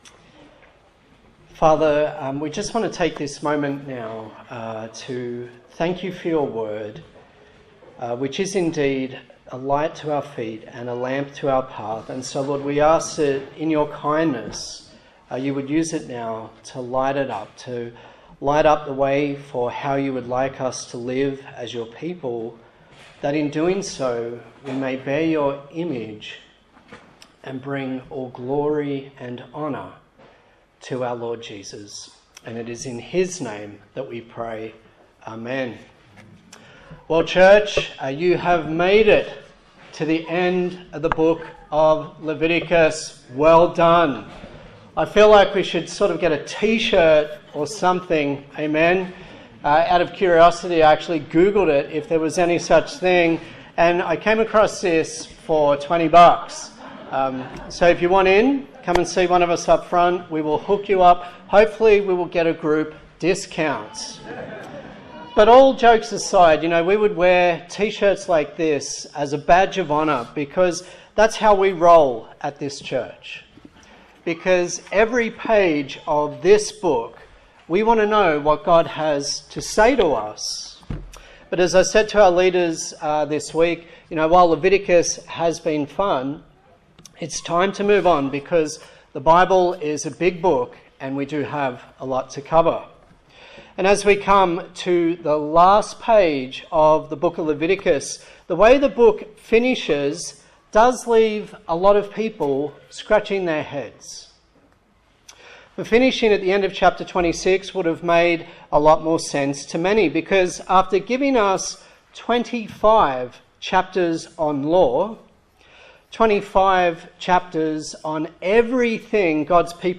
Service Type: Sunday Morning A sermon in the series on the book of Leviticus